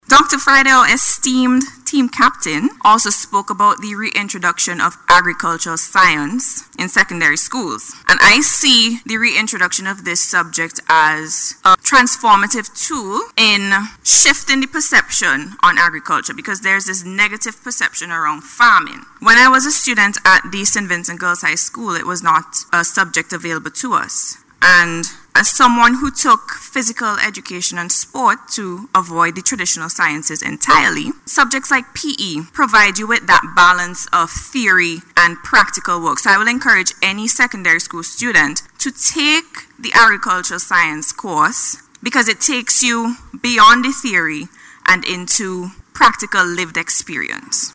She made this statement while contributing to the recent Budget Debate.